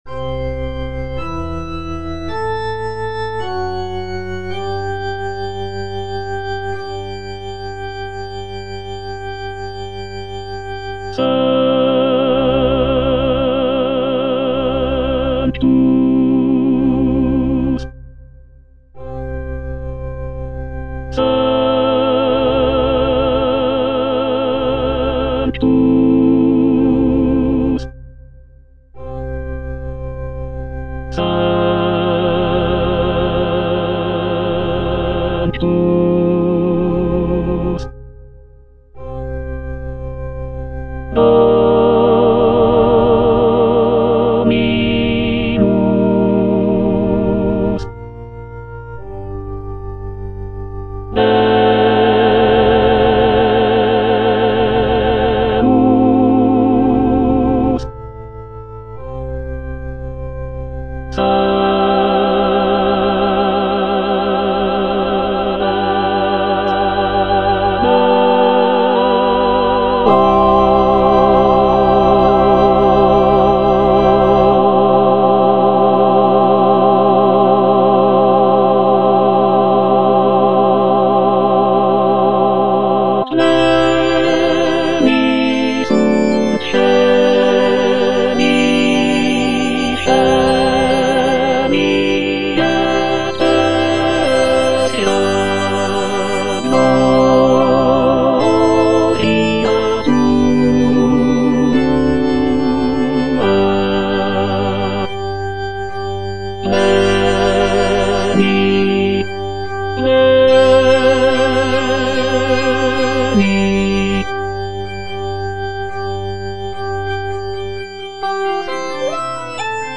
C.M. VON WEBER - MISSA SANCTA NO.1 Sanctus (tenor II) (Emphasised voice and other voices) Ads stop: auto-stop
"Missa sancta no. 1" by Carl Maria von Weber is a sacred choral work composed in 1818.